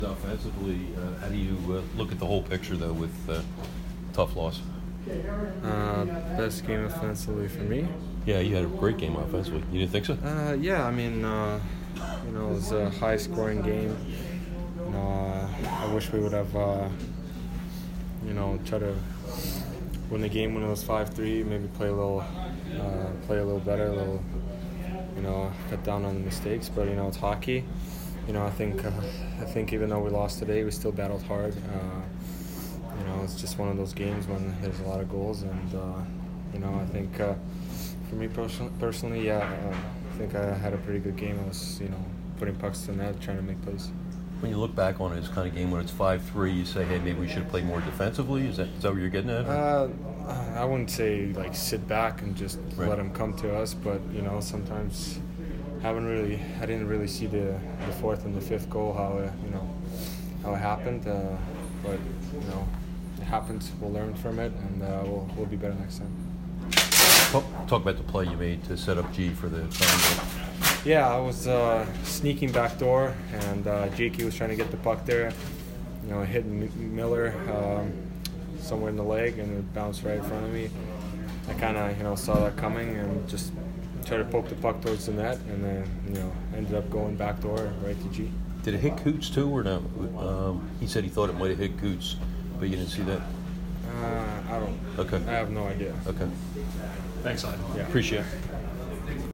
Ivan Provorov post-game 3/3